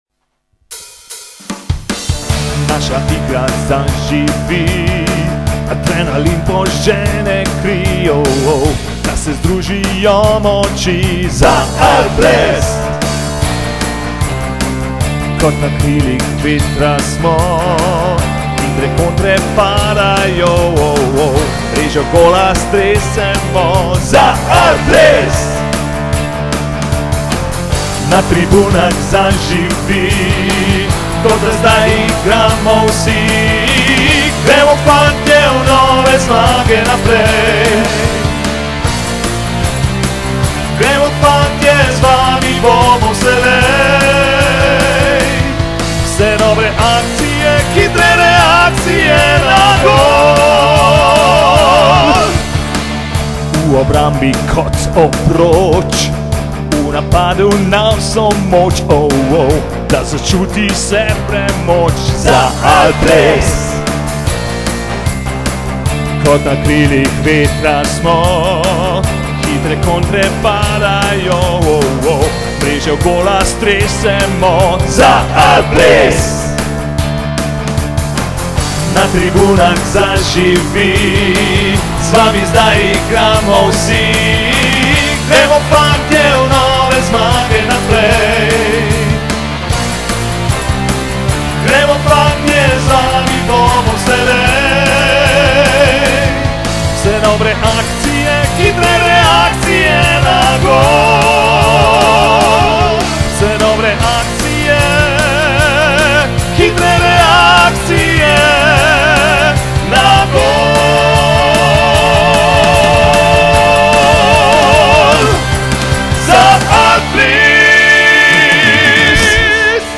rd-alples-himna.mp3